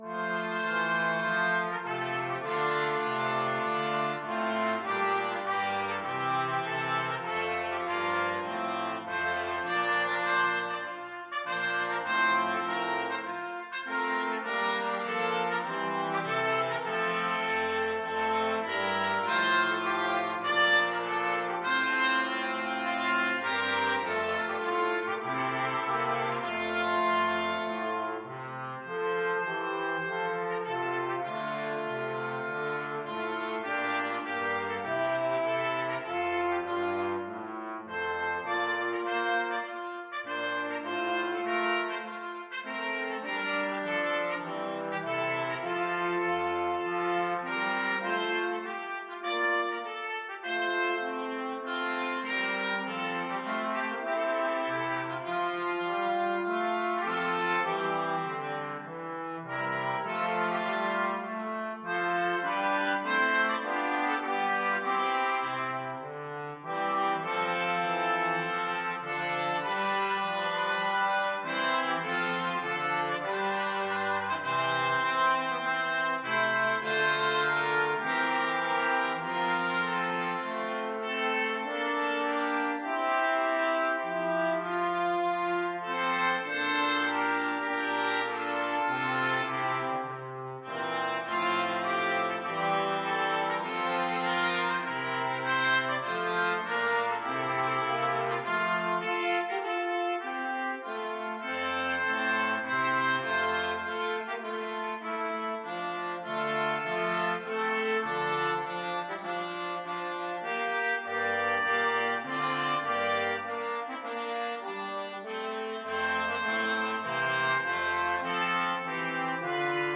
Trumpet 1Trumpet 2French HornTrombone
Allegro Moderato (View more music marked Allegro)
4/4 (View more 4/4 Music)
Classical (View more Classical Brass Quartet Music)